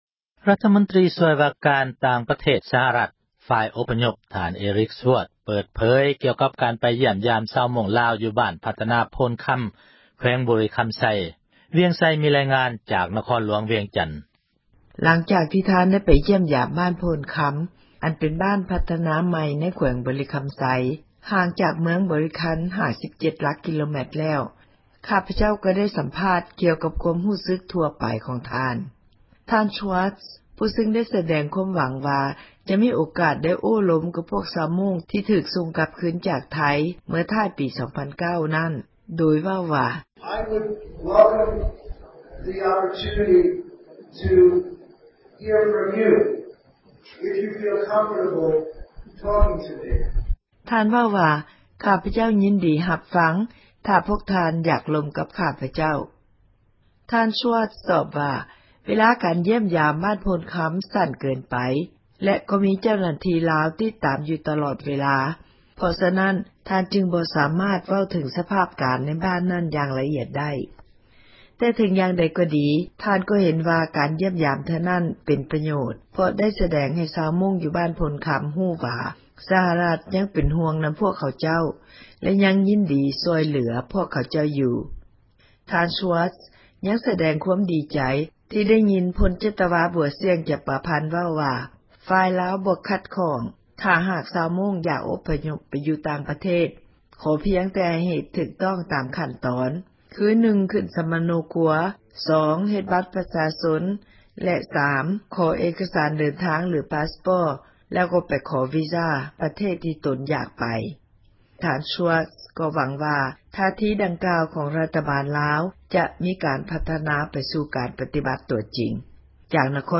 ມີລາຍງານ ຈາກນະຄອນຫຼວງ ວຽງຈັນ...